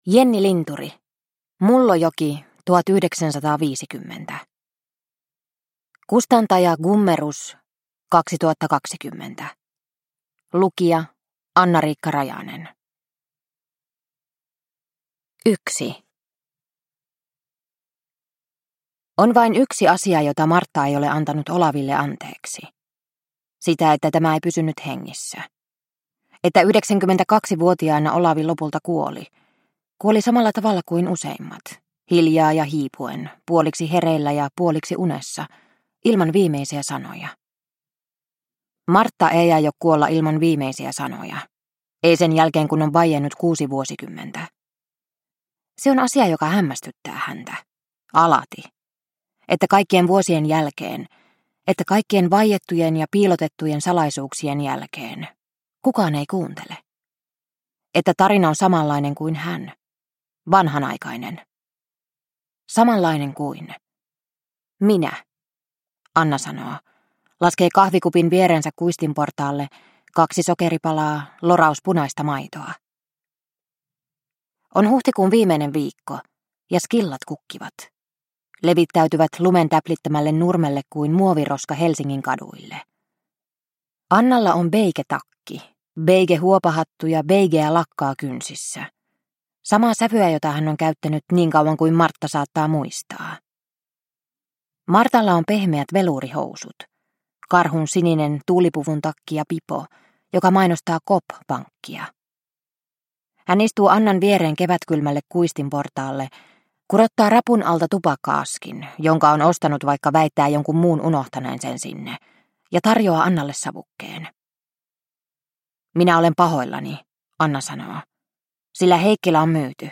Mullojoki, 1950 – Ljudbok – Laddas ner